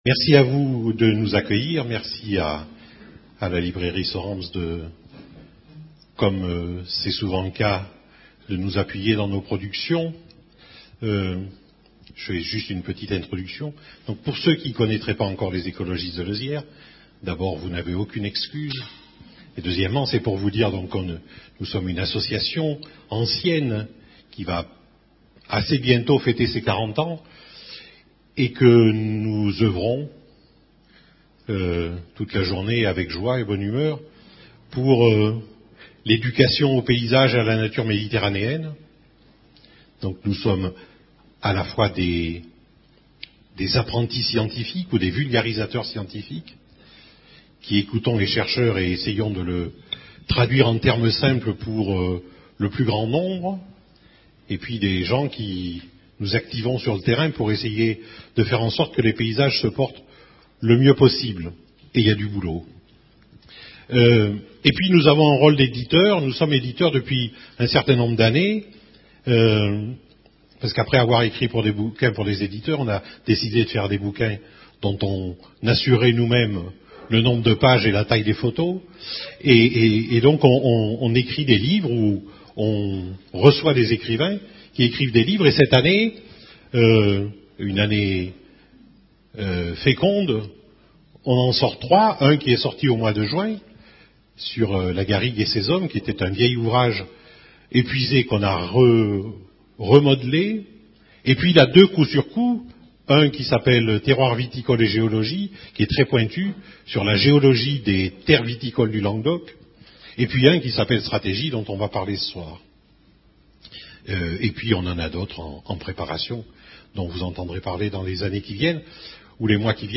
Rencontre littéraire